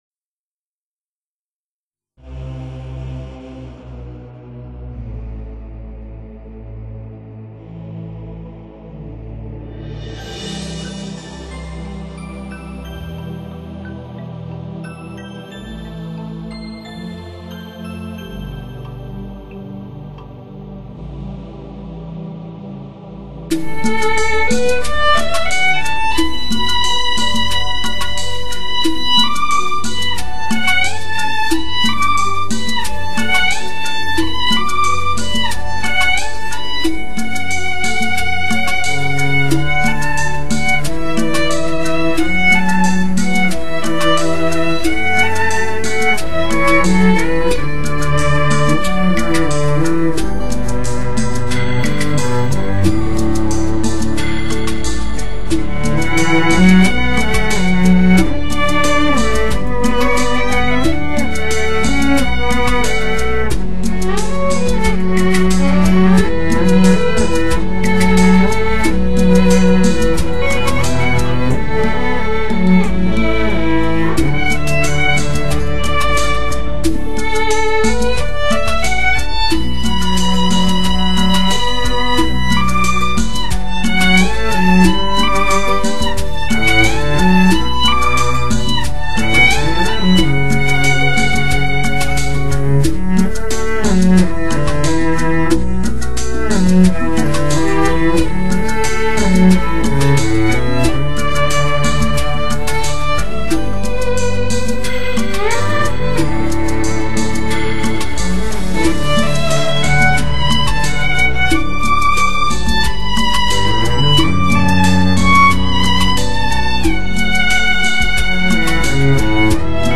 中国首张纯DSD制作，发烧友经典极品。
人声三重唱，各团首席乐手演奏。